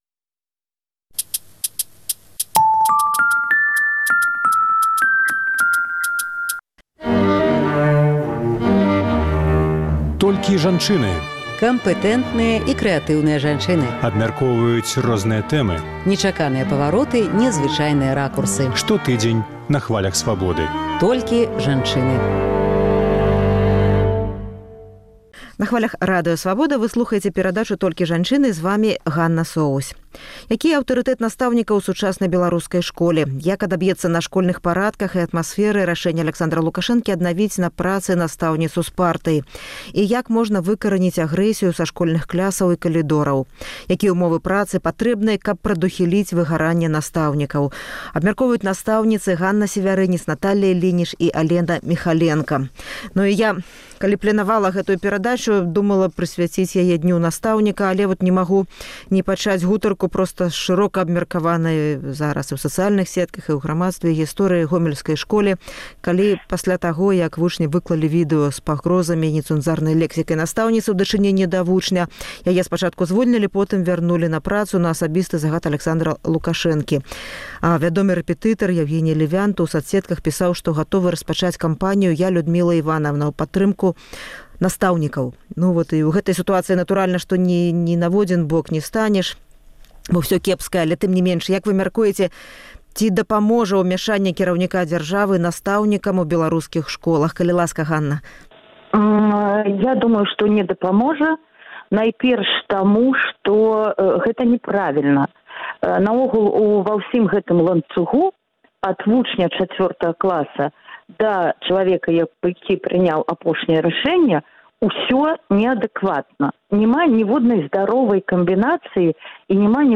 Як можна выкараніць агрэсію са школьных клясаў і калідорах? Якія ўмовы працы патрэбныя, каб прадухіліць «выгараньне» настаўнікаў? Абмяркоўваюць настаўніцы